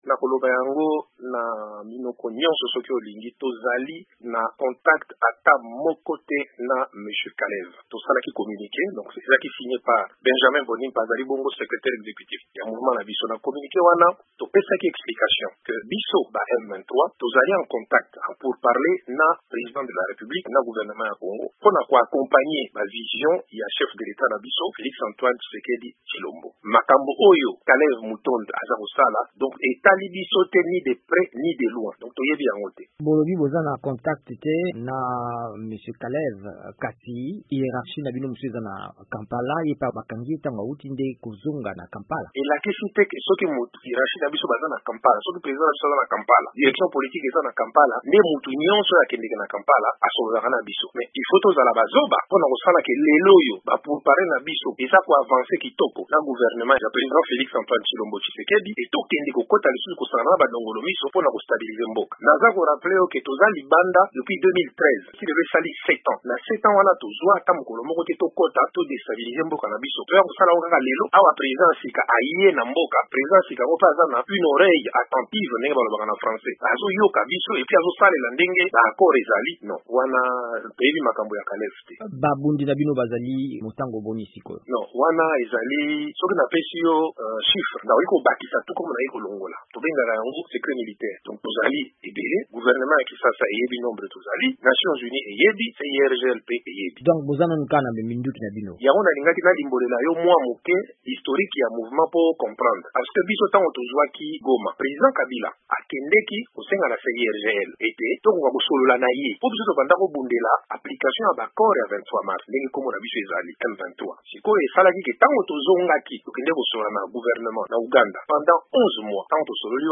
M23 elobi ezali na complot moko te Kalev Mutond (Interview)